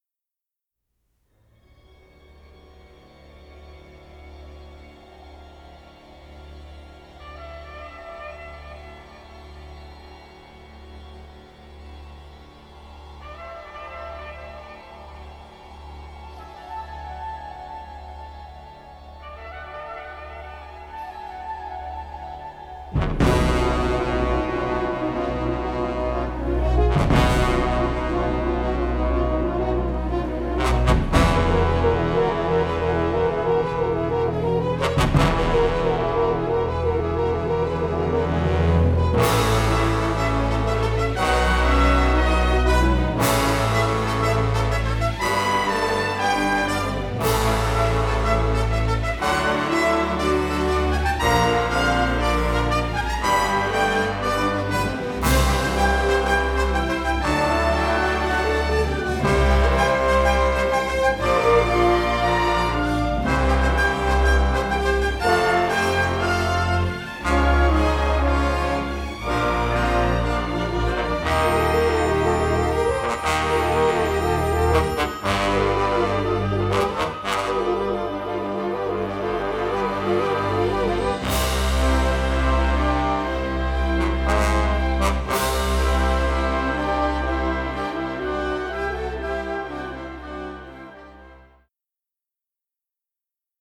all in splendid stereo sound.
orchestra